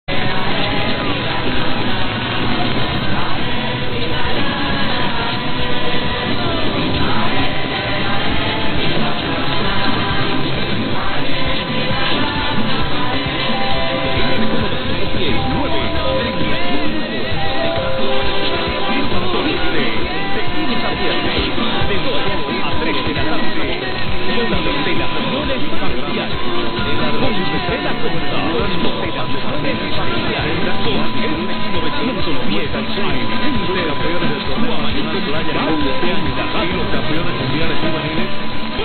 910 | VENEZUELA | YVRQ, Caracas, DEC 31 0202 - YVRQ doorbells in the beginning, then mentions of RQ and AM Center.